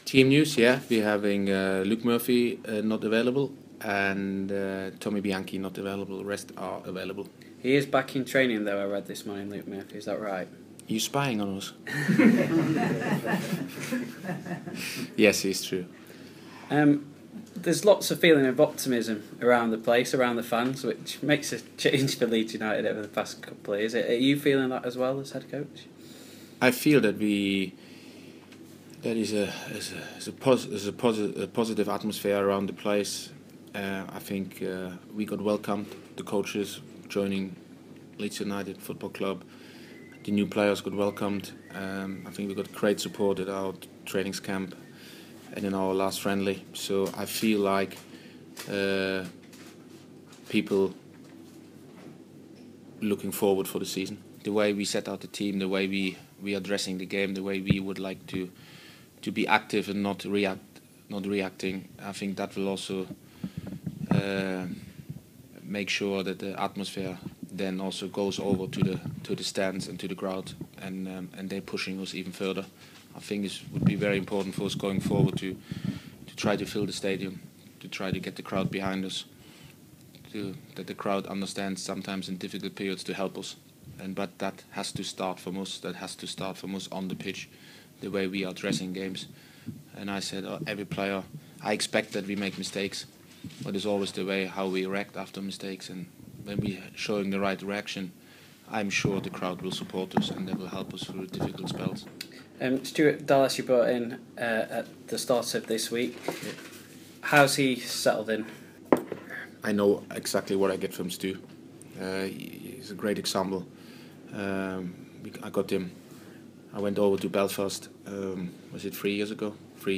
Leeds United boss Uwe Rosler speaks to Radio Yorkshire ahead of the opening day's fixture against Burnley